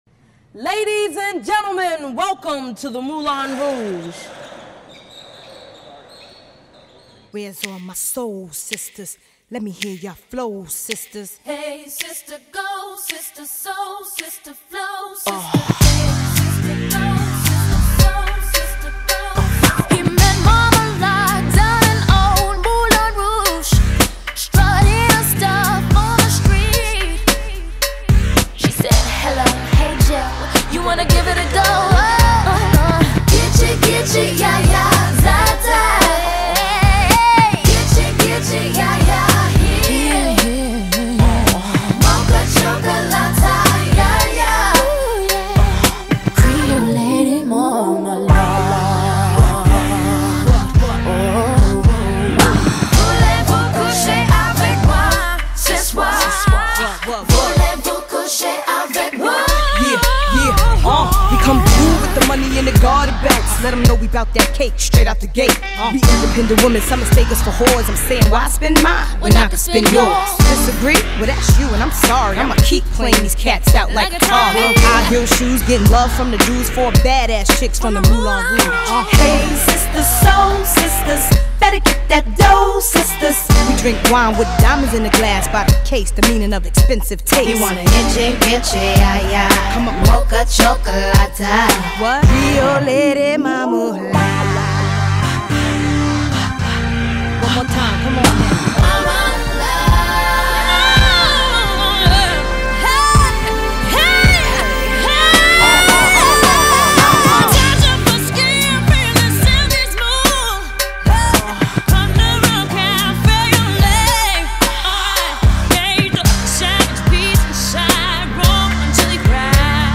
BPM109
Audio QualityCut From Video